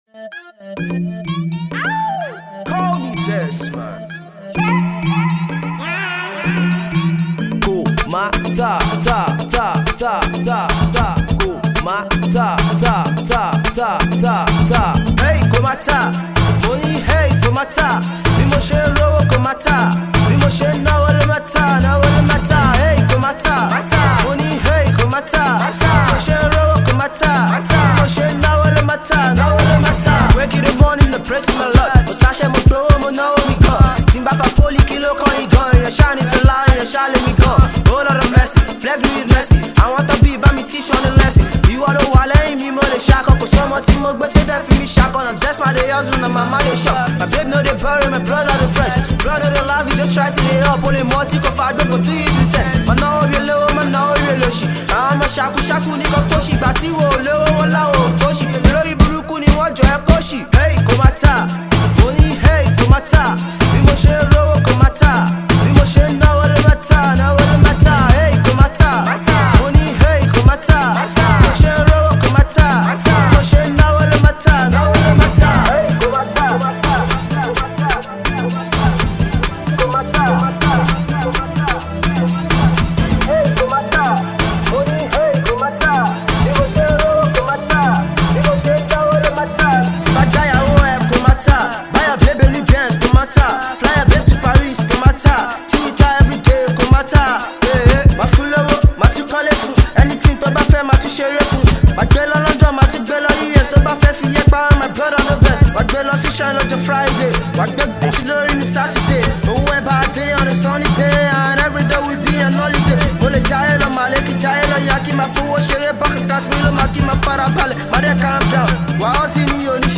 a dancehall jam which its gonna get u off your feet.